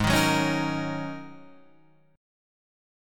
G#9sus4 chord